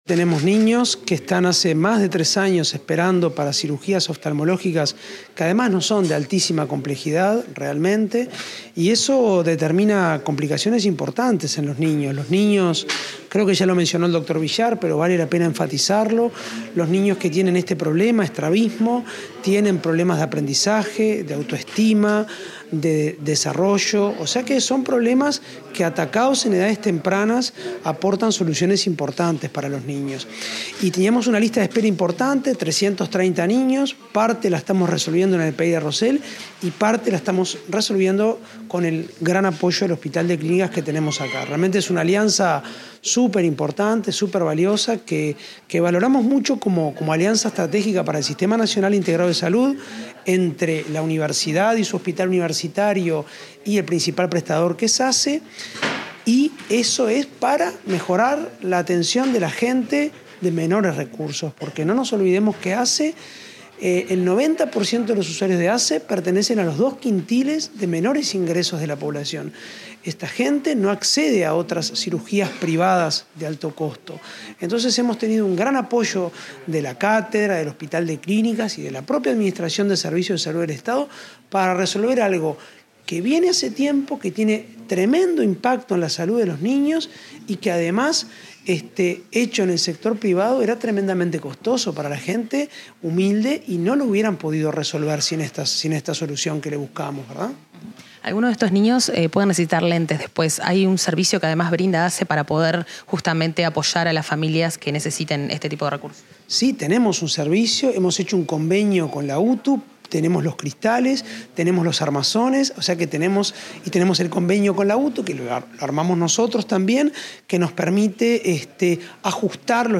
Declaraciones del presidente de ASSE, Álvaro Danza